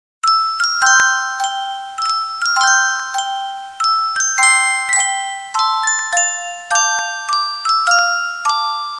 알림음 8_자장가.ogg